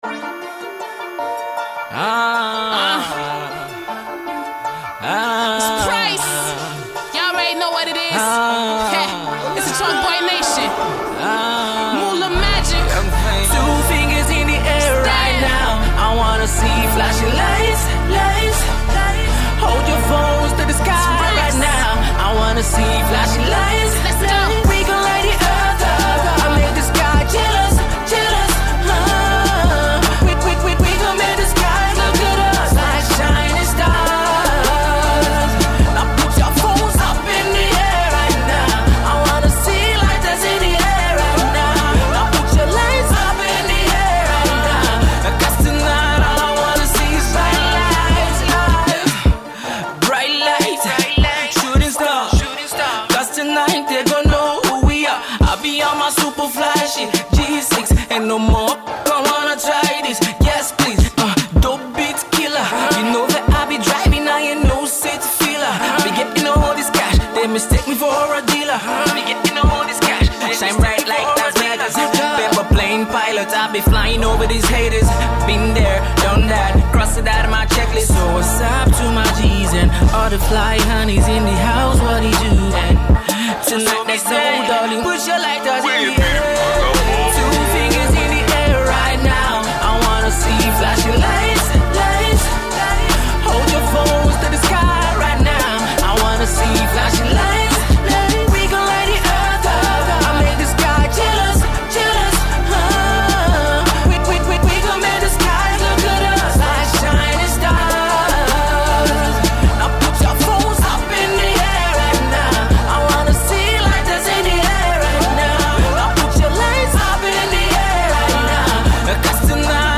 A mix of Afro Hip-Hop and American West Coast Hip-Hop